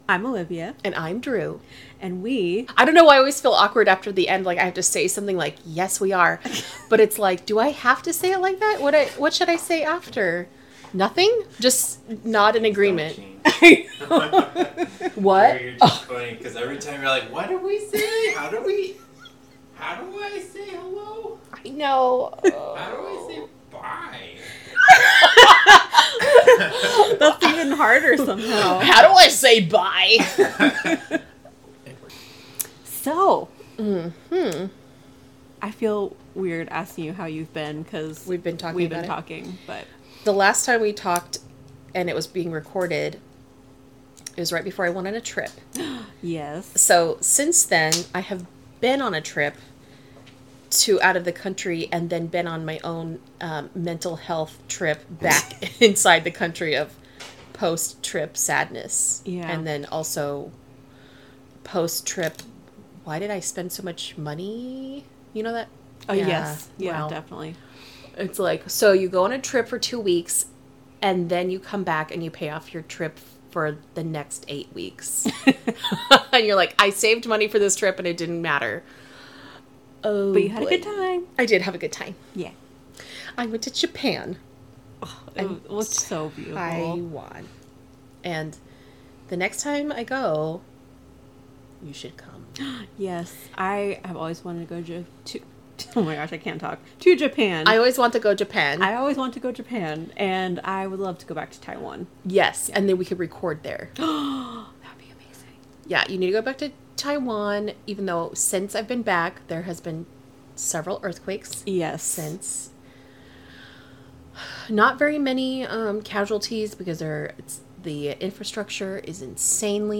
They love doing their research, keeping it light-hearted and trying to be funny.